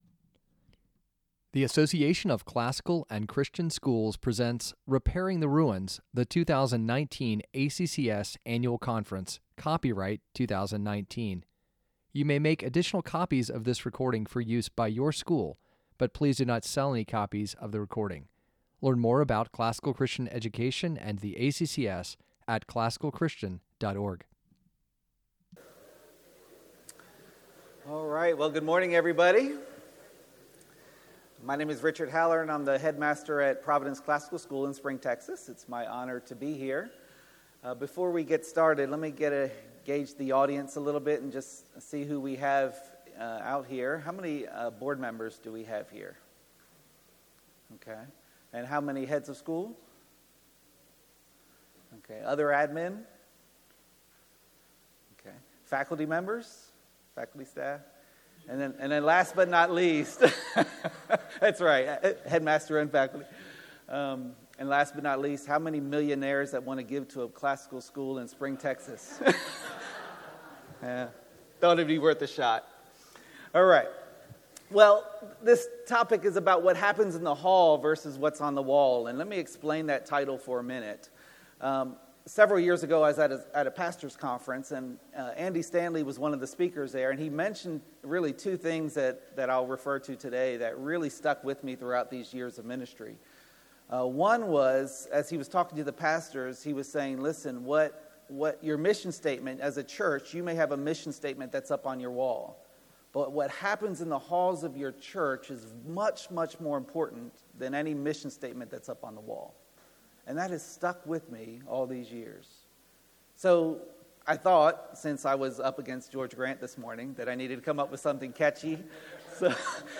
2019 Workshop Talk | 01:00:02 | Leadership & Strategic, Marketing & Growth